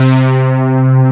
SYNTH C4.wav